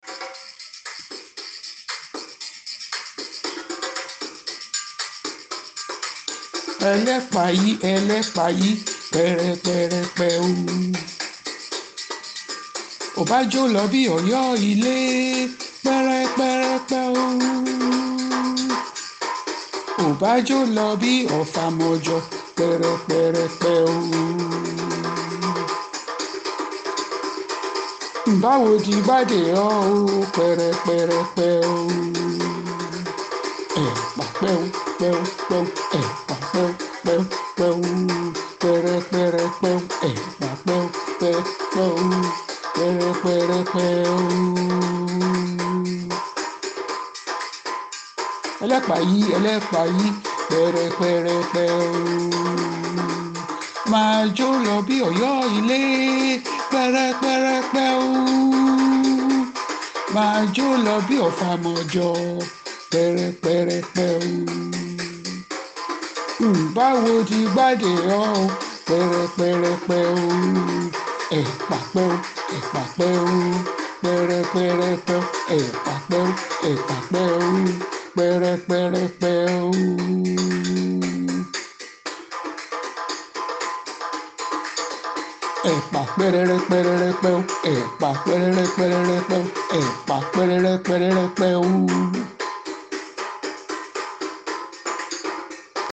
Bi Ijapa ti nkọrin yi, ti o dẹ nlu’lu pẹlu, ni ohun rẹ maa lọ s’oke a si tun maa lọ s’ilẹ to wa di orin aramada.